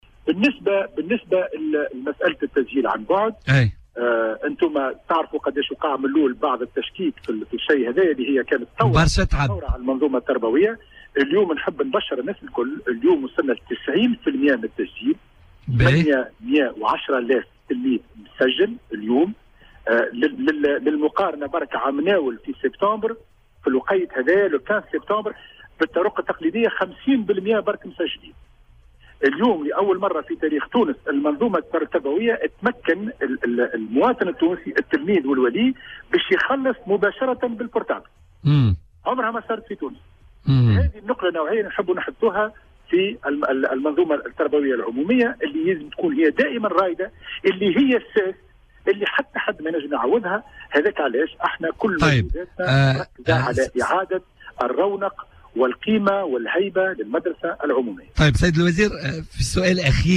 كشف وزير التربية حاتم بن سالم اليوم الخميس في مداخلة هاتفية مع "بوليتيكا" على "الجوهرة اف أم" عن نسبة التسجيل عن بعد إلى غاية اليوم الخميس بالنسبة لتلاميذ الاعدادي والثانوي.